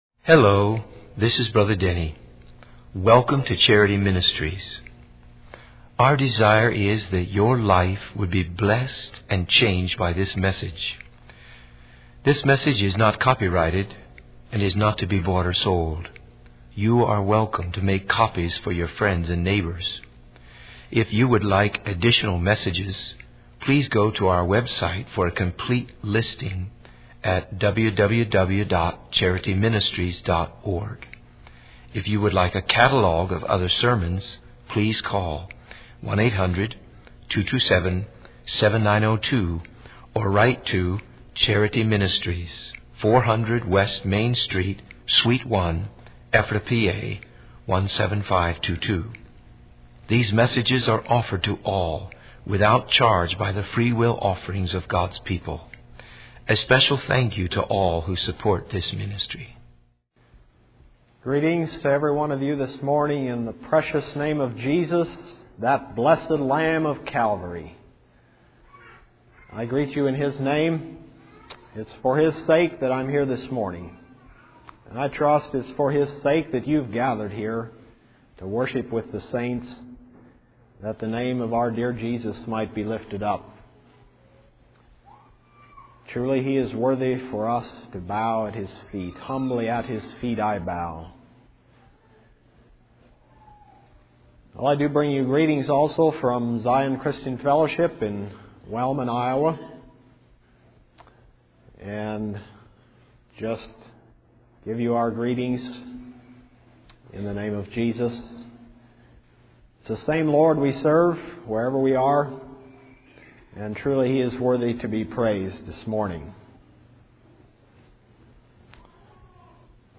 In this sermon, the speaker emphasizes the importance of setting our affection on heavenly things and pursuing the high calling of God. He encourages everyone to recognize that God has a personal call for each individual, regardless of the size or significance in the eyes of others.